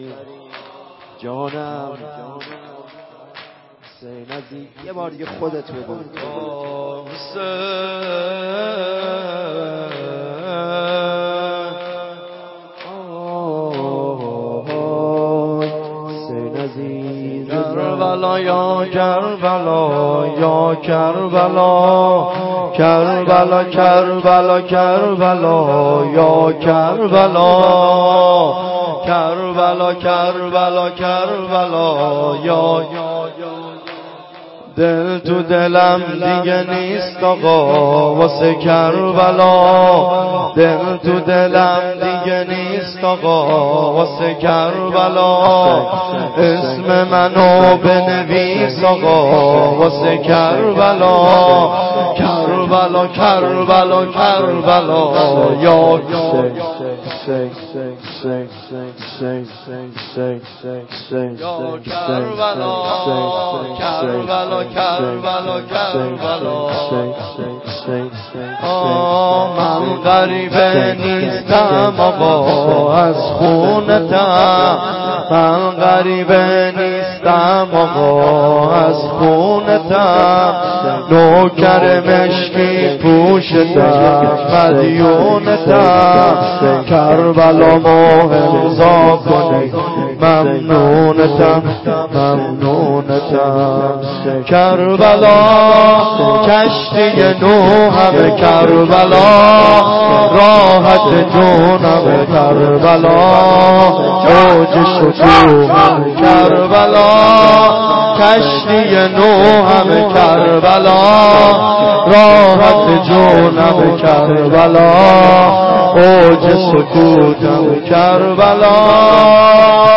جلسه هفتگی 26-9-93.mp3